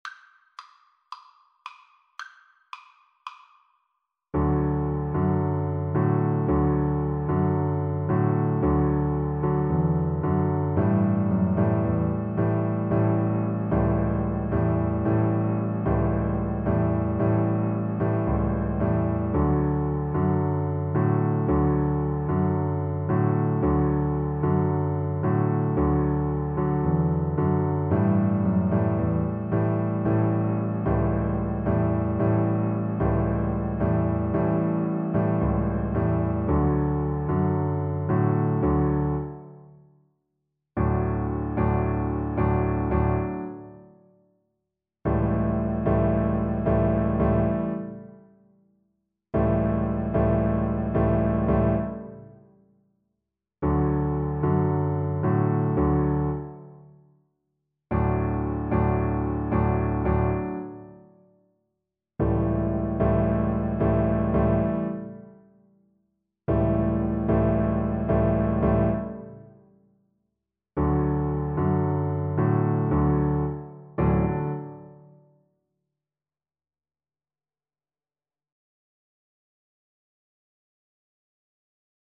Double Bass
D major (Sounding Pitch) (View more D major Music for Double Bass )
Presto (View more music marked Presto)
World (View more World Double Bass Music)